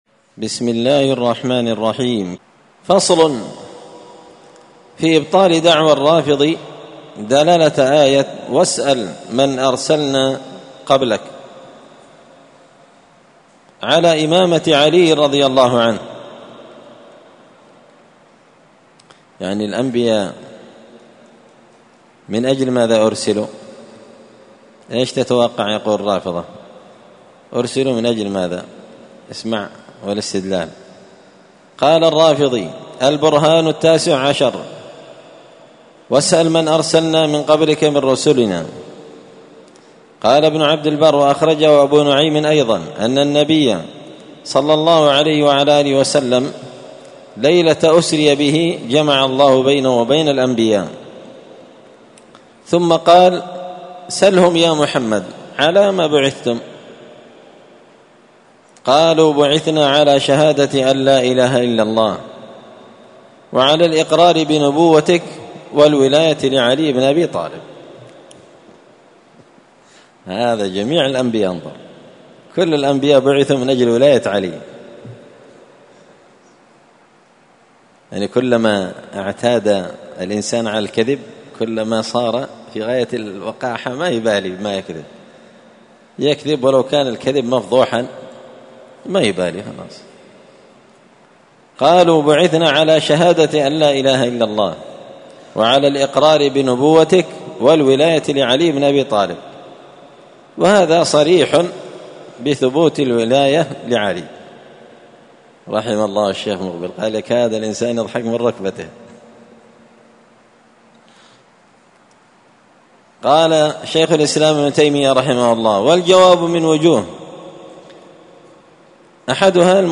الأثنين 12 صفر 1445 هــــ | الدروس، دروس الردود، مختصر منهاج السنة النبوية لشيخ الإسلام ابن تيمية | شارك بتعليقك | 69 المشاهدات
مسجد الفرقان قشن_المهرة_اليمن